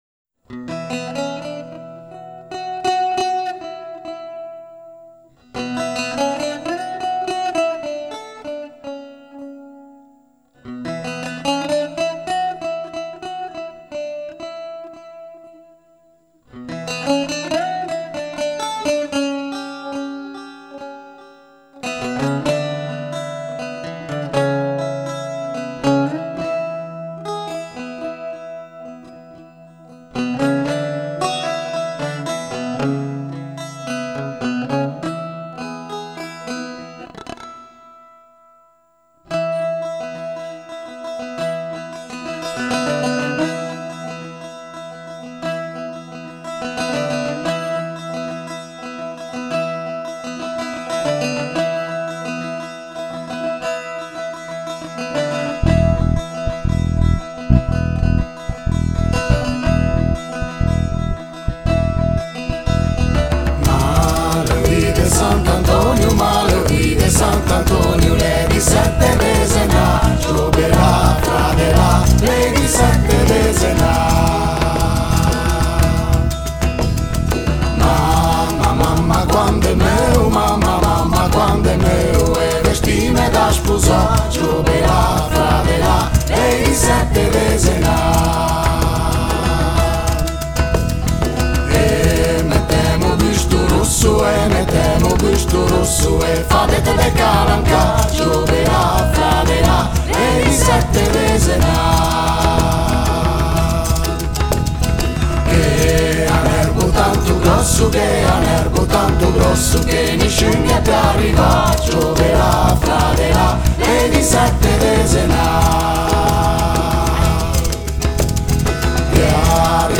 bouzouki
basso / bass
djembè
riq, bodhran
The piece is a stornello of Ligurian origin, also found in southern Piedmont, which the inhabitants of Carloforte have inherited and handed down; it is perhaps the only truly ancient piece of those written in the local language and is currently sung at the “casciandre” (convivial meetings), in serenades and for the feast of Sant’Antonio Abate on January 17 which marks the beginning of the Carnival period.